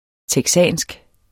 Udtale [ tεgˈsaˀnsg ]